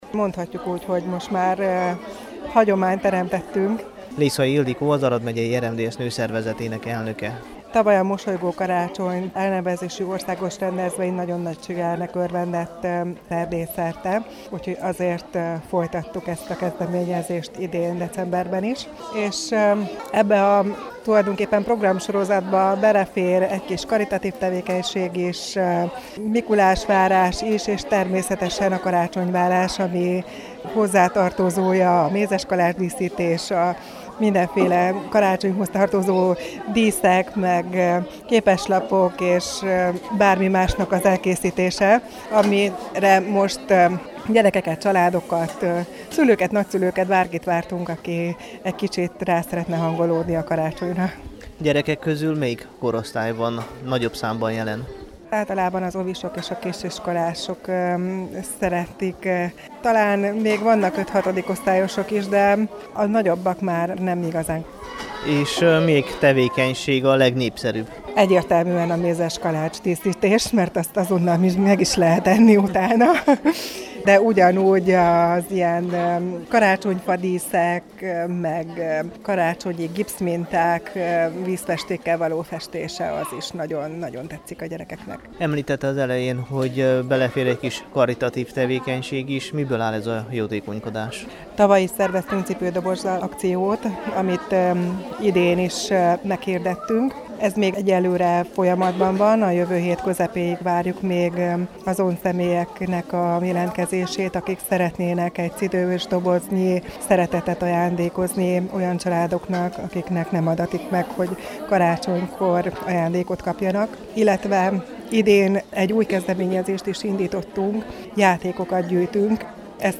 A megyei RMDSZ Nőszervezetének és az Aradi Magyar Anyukák és Gyermekek Klubjának közös szervezésében idén is megtartották a Mosolygó karácsony elnevezésű ünnepváró délutánt.
Pénteken a Csiky Gergely Főgimnázium dísztermében az óvodásoknak és kisiskolásoknak szerveztek kézműves-foglalkozásokat, amelyen karácsonyi díszeket, képeslapokat készíthettek a gyereket, színezhettek és mézeskalácsokat díszíthettek ki.